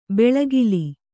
♪ beḷagili